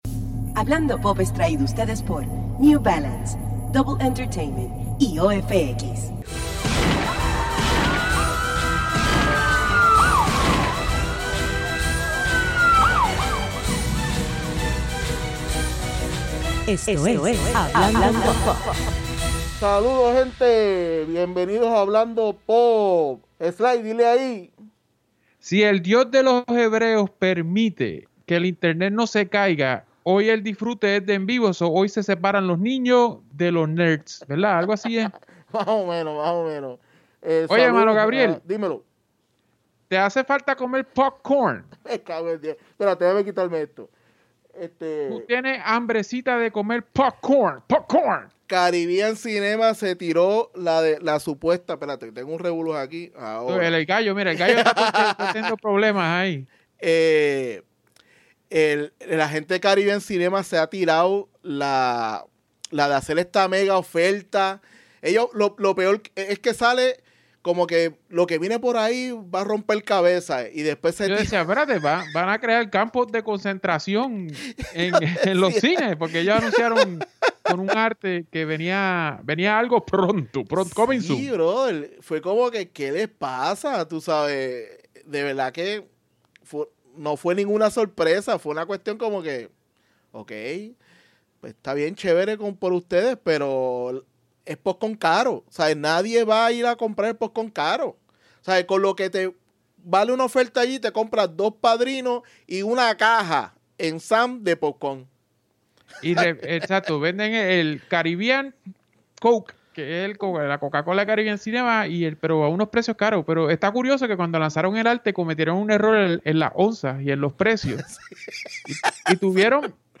Hablando PoP se fue LIVE y vacilamos un rato espero que la pasaran tambien como nosotros.